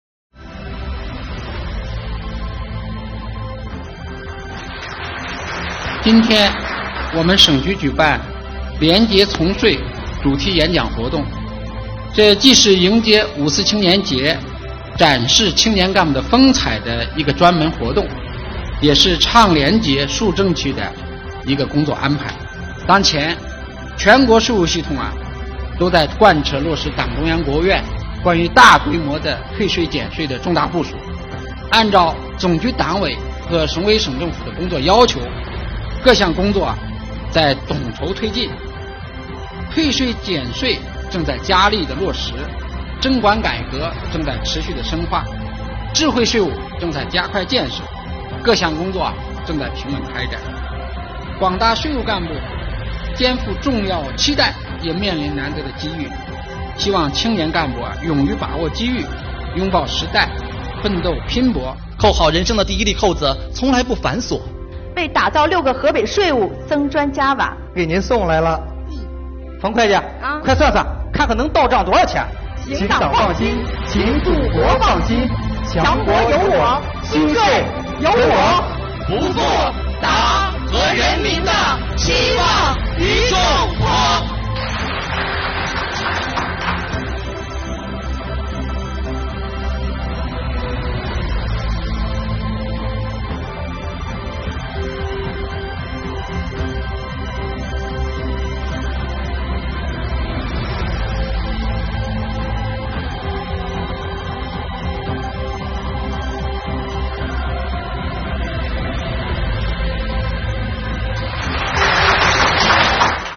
在河北，国家税务总局河北省税务局机关近日举行扣好廉洁从税“第一粒扣子”青年廉政主题演讲比赛。